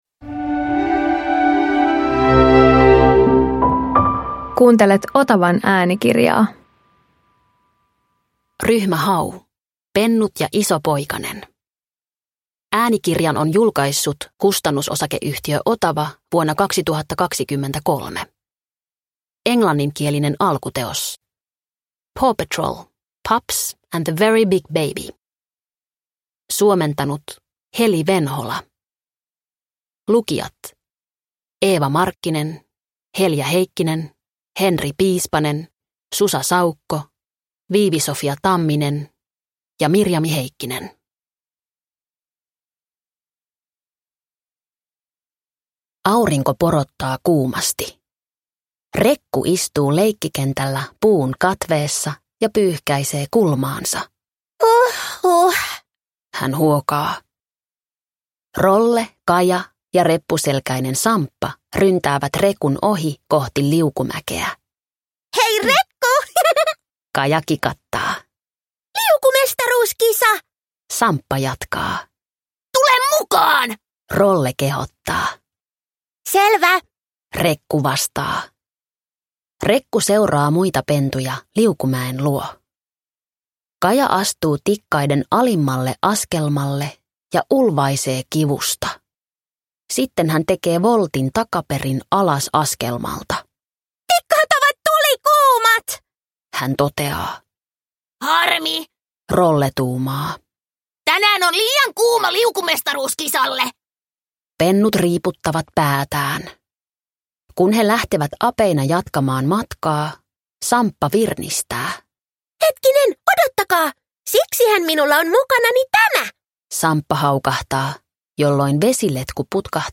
Ryhmä Hau Pennut ja iso poikanen – Ljudbok – Laddas ner